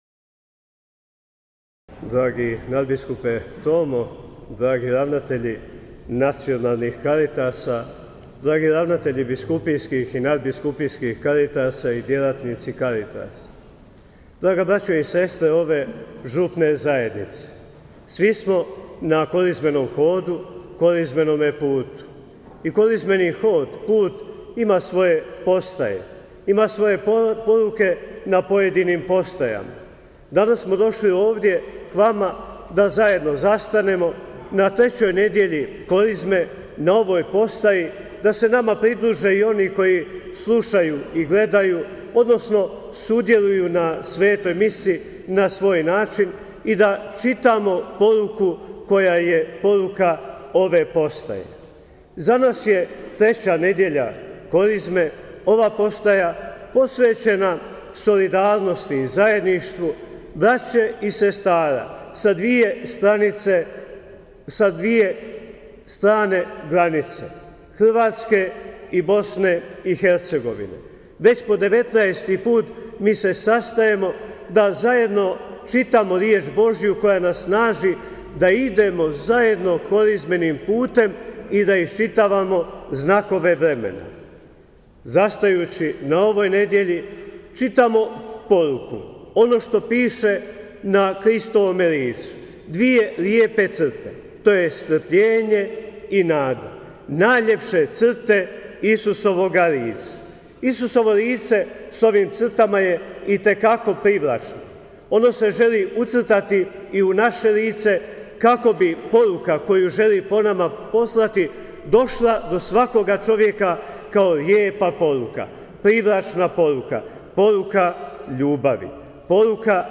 Hrvatska radiotelevizija, RTV Herceg-Bosne, Hrvatski katolički radio te još nekoliko radiopostaja su izravno prenosili euharistijsko slavlje iz žepačke župne crkve.